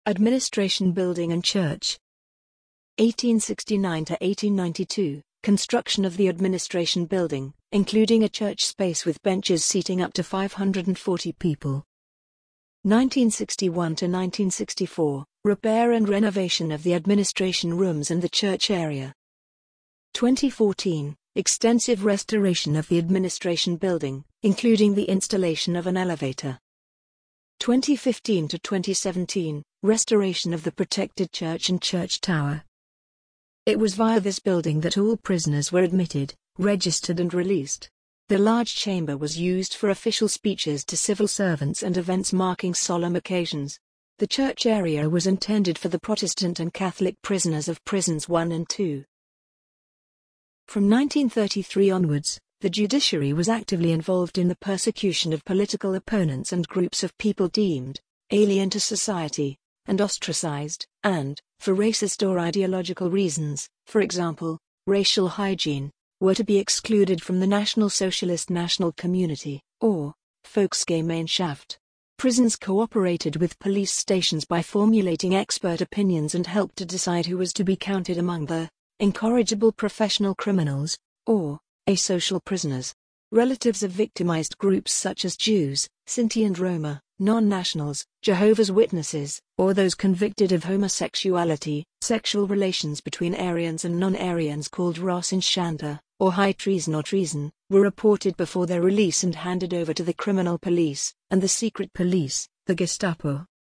Audio-Version des Artikels